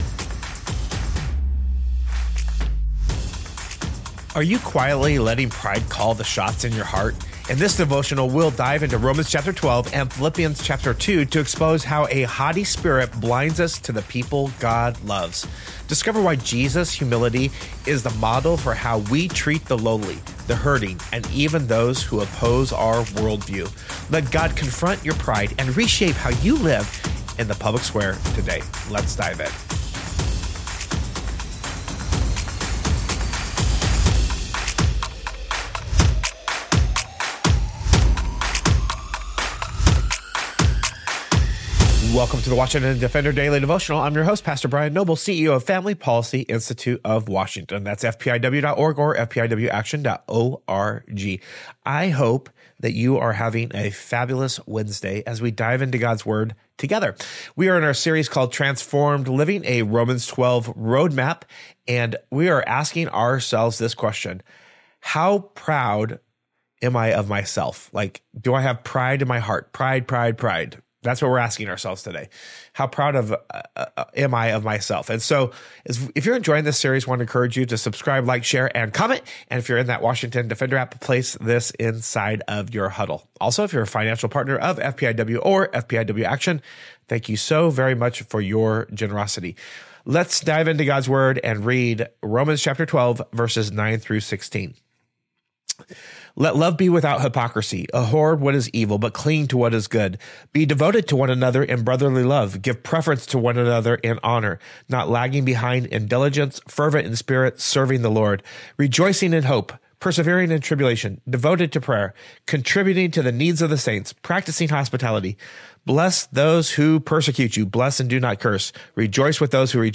The episode ends in prayer, asking God to humble our hearts so we can defend and advance biblical values with Christlike love.